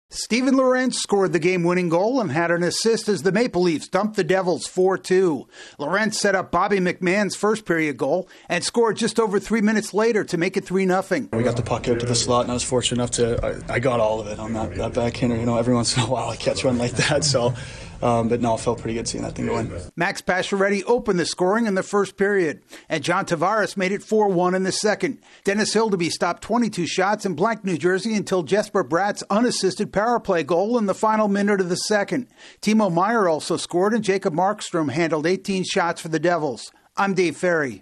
The Maple Leafs rebound from their 1-0, season-opening loss to the Canadiens. AP correspondent